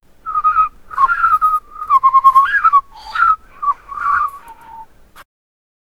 arctic fox whimpering sound effect with snowy sound in the background 0:06 Created May 8, 2025 3:05 PM Blizzard howls in Arctic tundra, snow crunches under silver reindeer’s hooves.
arctic-fox-whimpering-sou-fmv4pqsz.wav